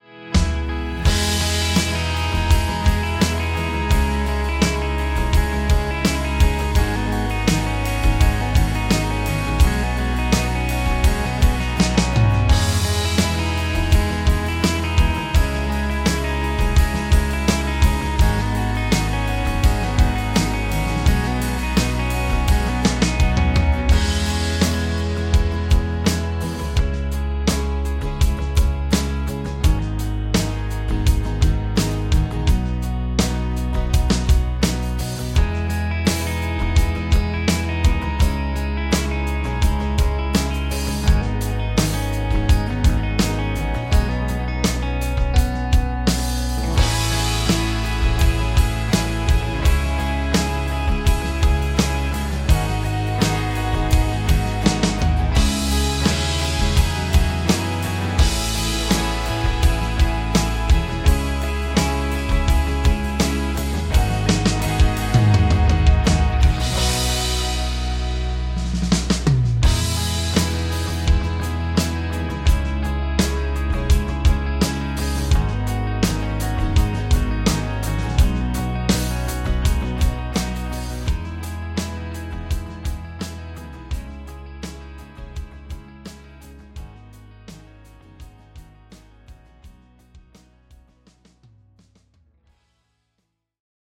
DW Soundworks 是 DW（Drum Workshop）与 Roland 联合开发的旗舰级虚拟鼓音源插件，主打真实原声鼓采样、多麦克风混音、深度自定义与可扩展音色库，是追求顶级原声鼓质感的制作人首选。